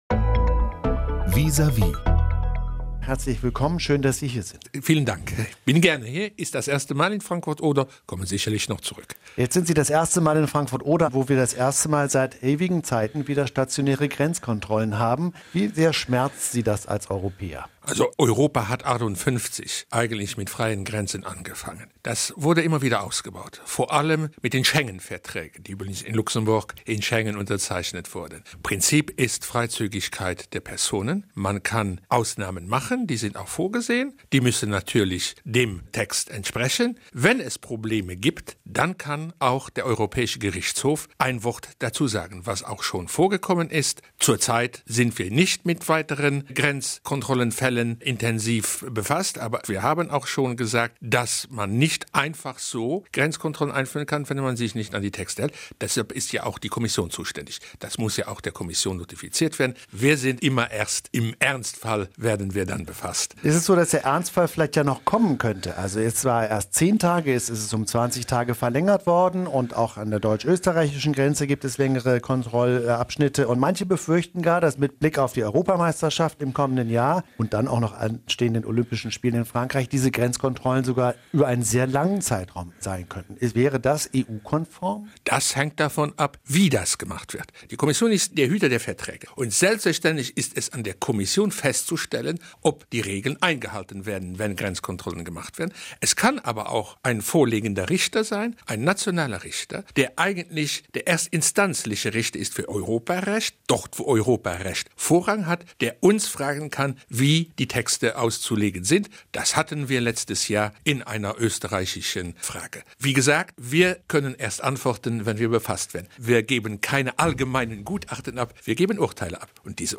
Er sprach mit Biltgen über die Arbeit der Juristen in Straßburg, die dafür da sind, die Einhaltung europäischer Rechte und Regeln zu überwachen. Ein aktuelles Thema für die Region sind etwa die neuen Grenzkontrollen.